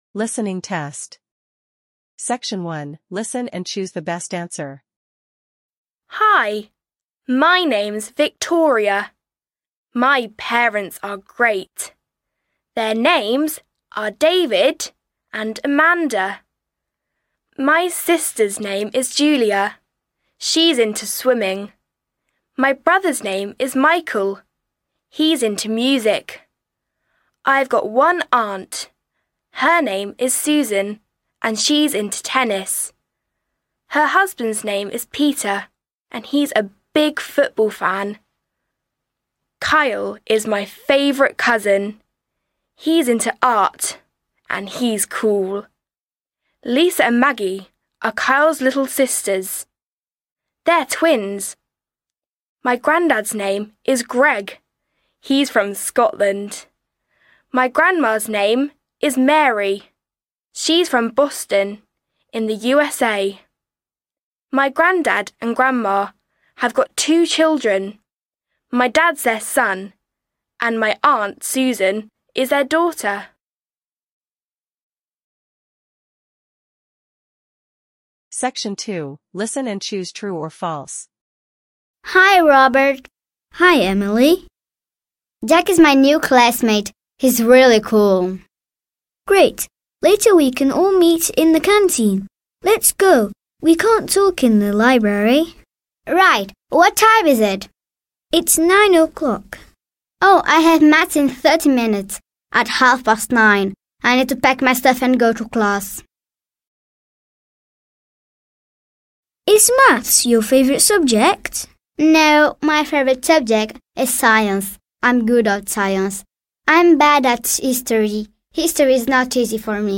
LISTENING TEST
Rung-Chuong-Vang-Listening-test-Grade-4-5.mp3